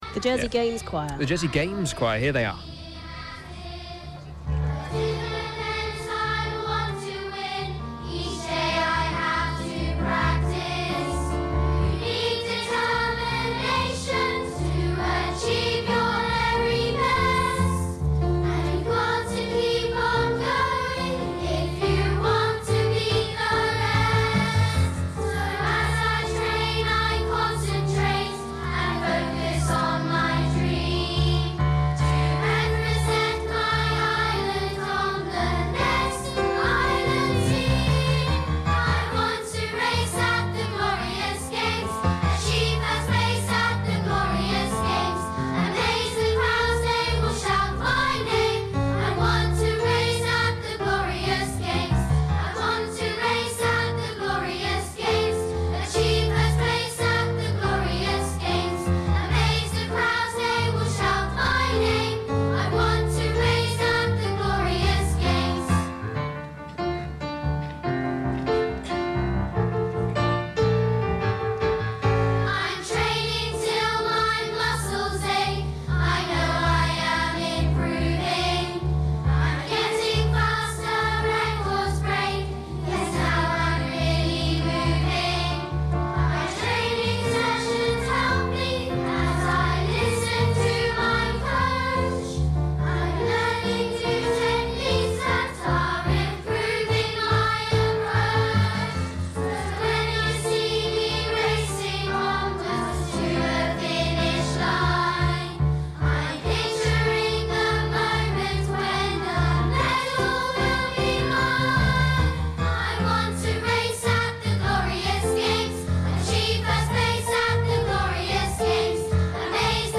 The Jersey Games Choir made up of school children from across the Island sing before the Opening Ceremony. They perform The Glorious Games.